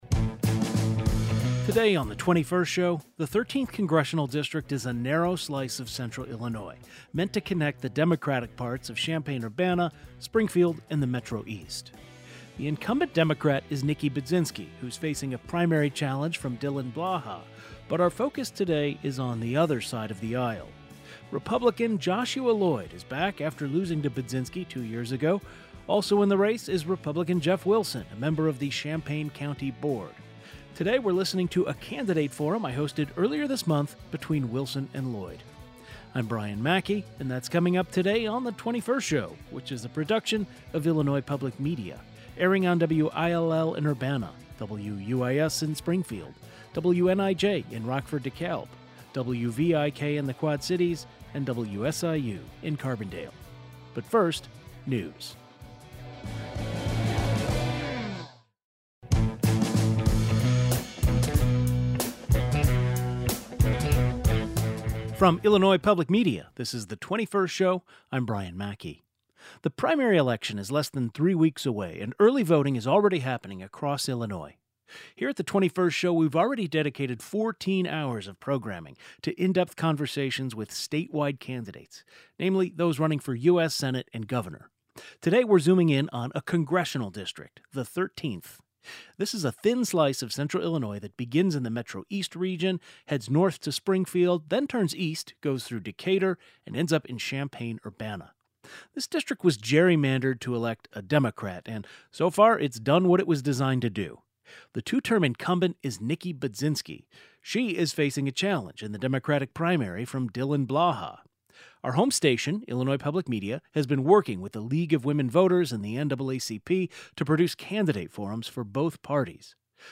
Two candidates vying for the GOP nomination in the 1L-13 congressional race discuss many issues surrounding domestic policy including the economy, healthcare, ICE agents, and a potential national ban on abortion at the IL-13 Republican Candidate Forum sponsored by Illinois Public Media, the Champaign County NAACP, and the League of Women Voters.